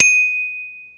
question_markTermékkör Gyerek csengő
Katicás csengő, elsősorban gyermek kerékpárokra,
38 mm átmérőjű csengő pengetővel